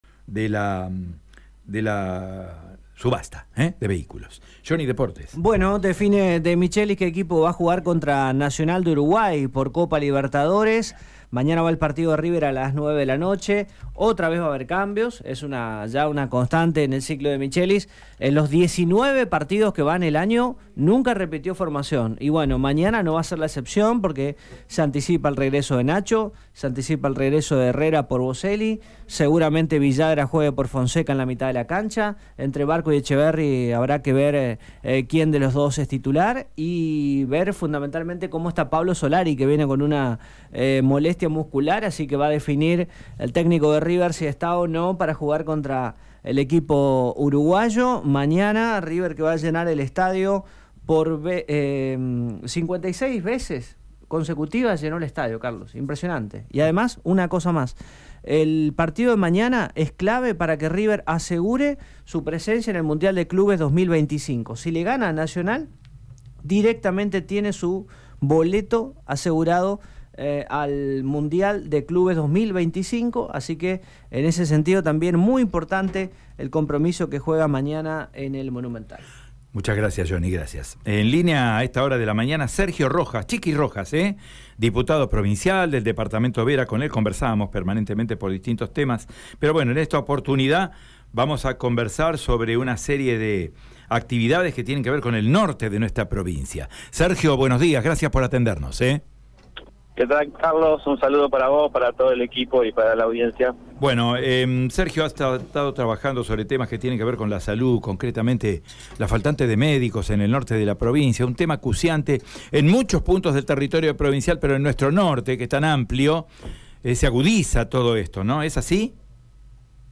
Escucha la palabra de Sergio Rojas en Radio EME: